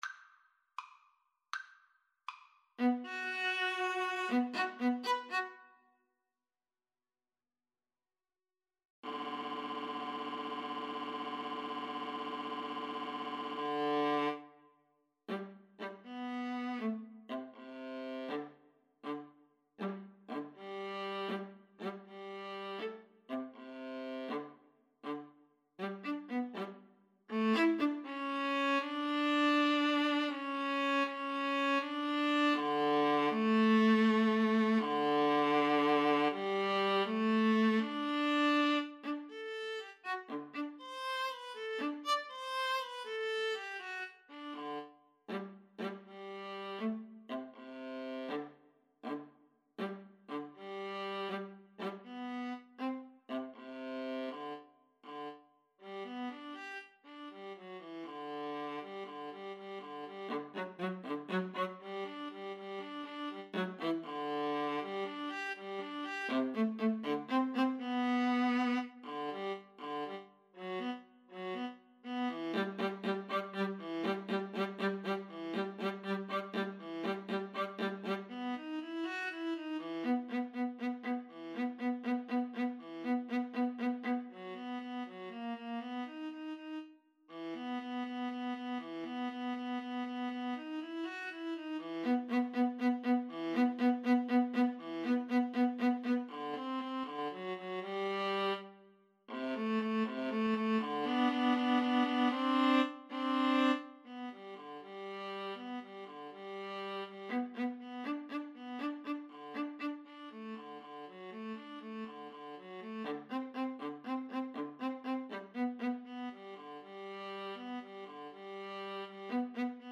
Free Sheet music for Violin-Viola Duet
G major (Sounding Pitch) (View more G major Music for Violin-Viola Duet )
6/8 (View more 6/8 Music)
~ = 100 Allegretto moderato .=80
Classical (View more Classical Violin-Viola Duet Music)